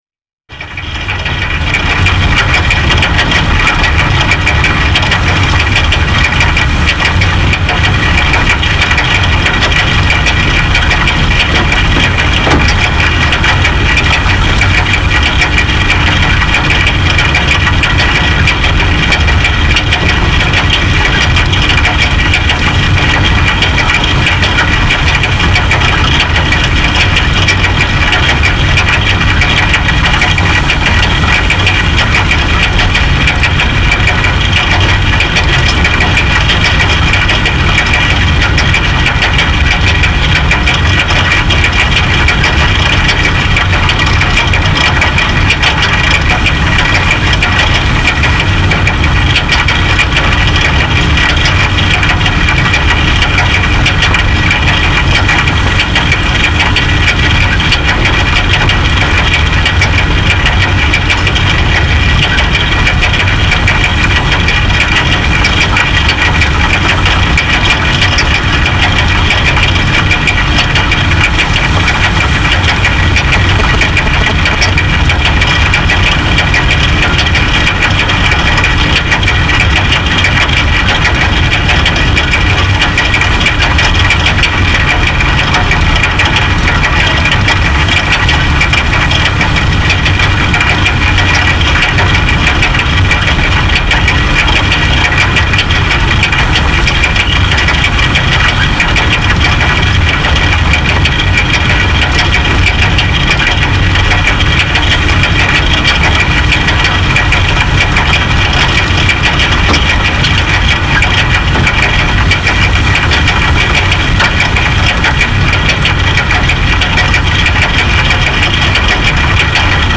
I hope they never fix this wonderful escalator, whose whir and clunk lull me on my daily commute home.
karlsplatz.aif